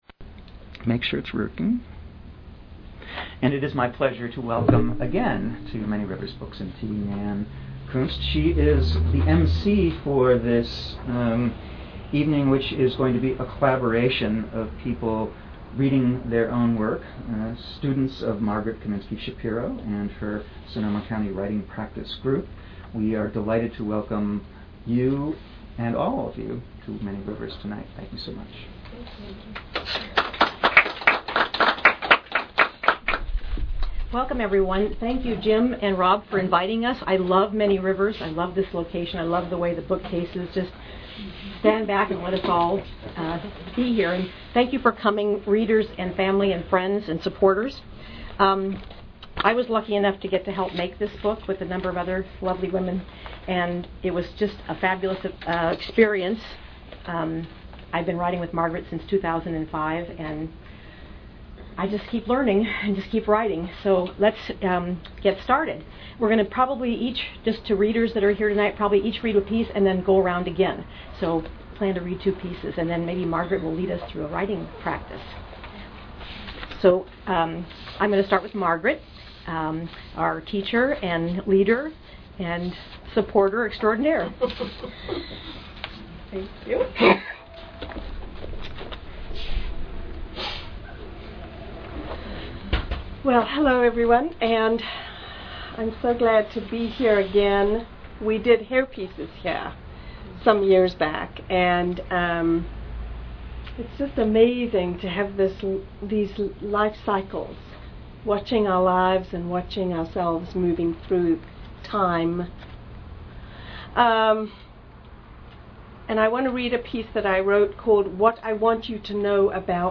Archive of an event at Sonoma County's largest spiritual bookstore and premium loose leaf tea shop.
A Reading of Prose and Poetry